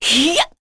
Seria-Vox_Attack3.wav